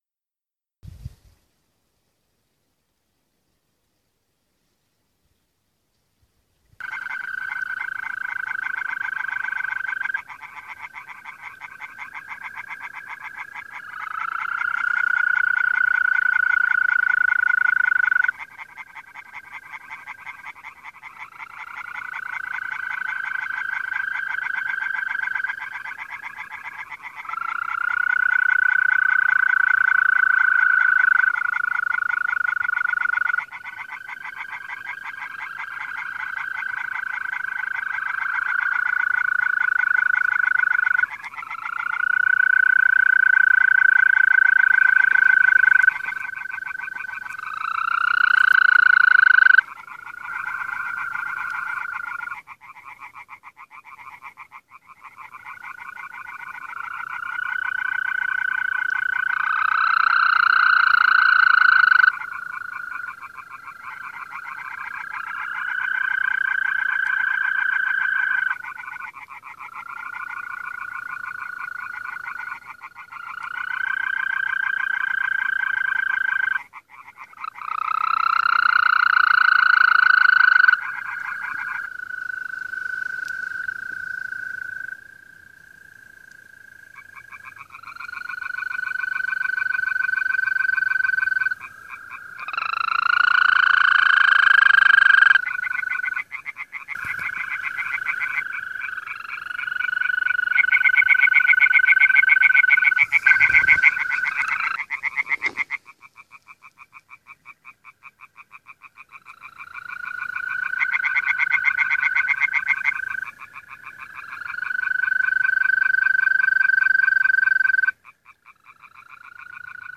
Rospo smeraldino
Bufo viridis
Canto-Rosposmeraldino-Rospo-Bufo-viridis.mp3